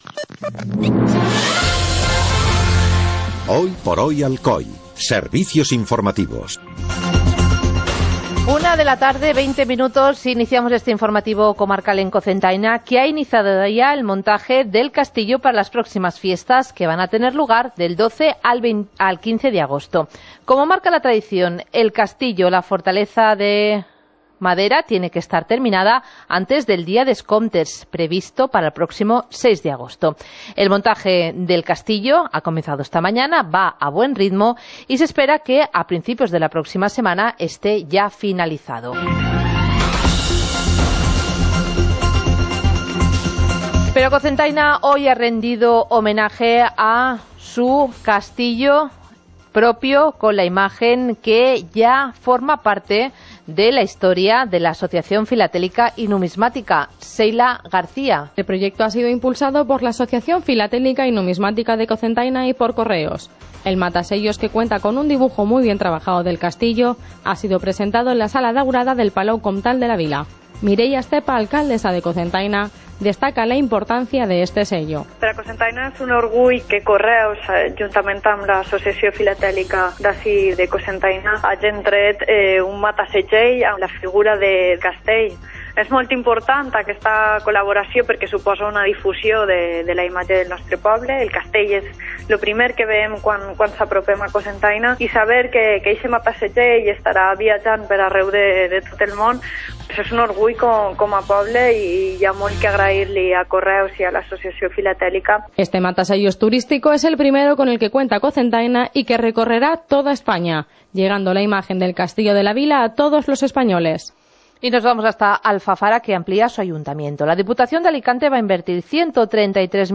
Informativo comarcal - jueves, 21 de julio de 2016